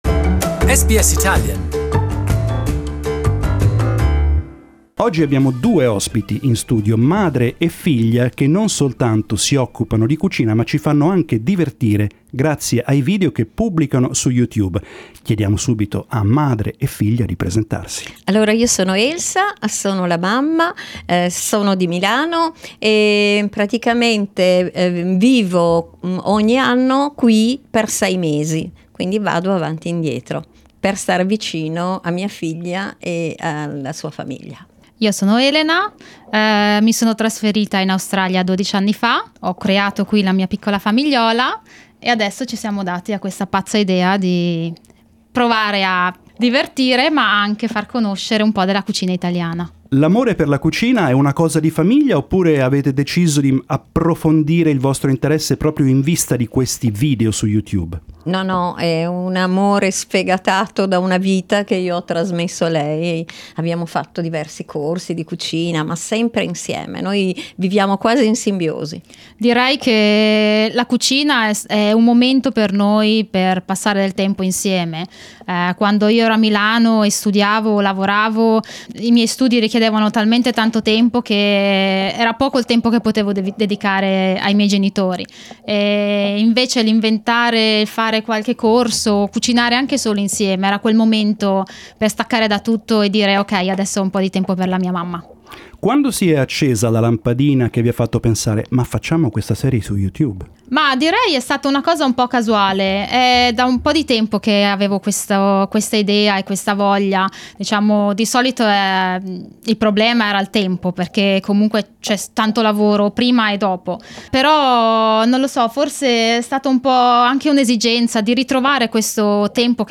They visited us in ours studios.